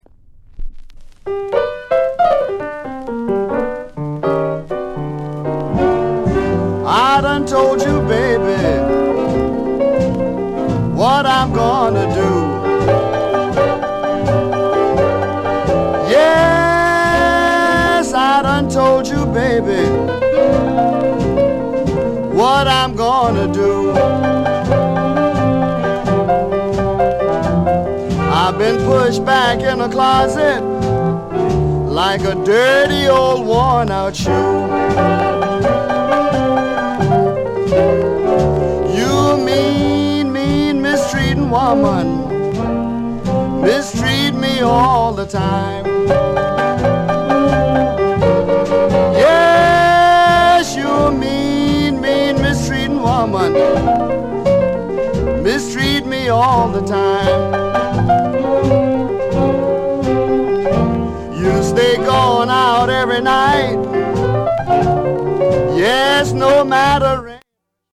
R&B INST